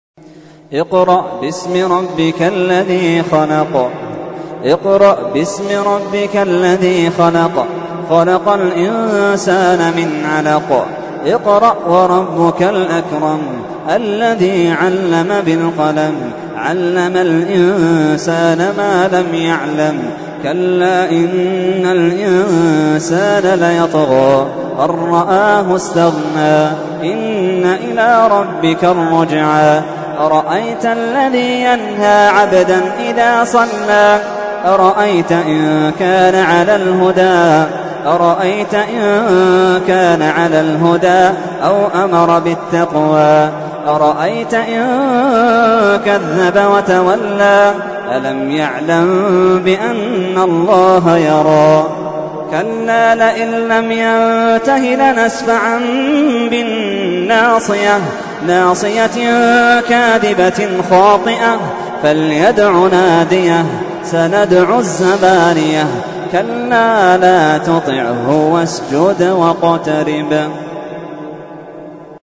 تحميل : 96. سورة العلق / القارئ محمد اللحيدان / القرآن الكريم / موقع يا حسين